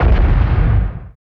50 XPL KIK-R.wav